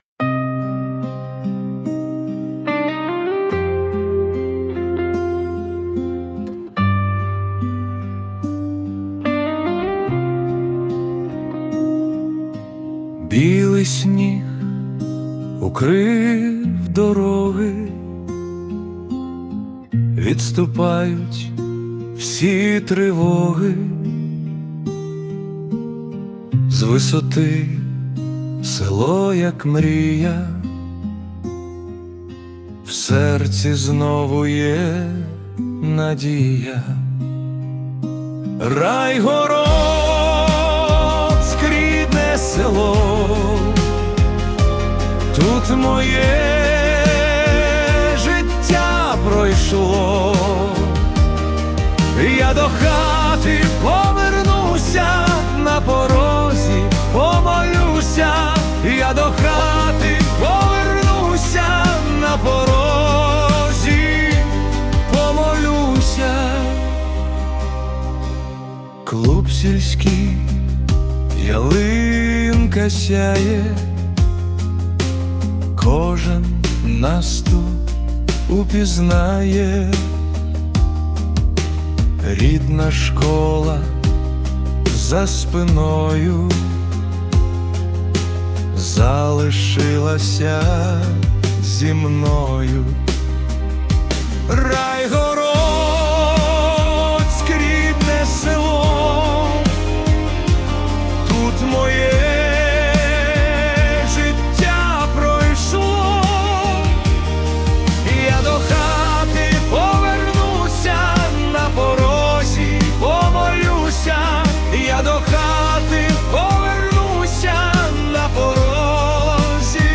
🎵 Жанр: Акустична балада / Сучасний романс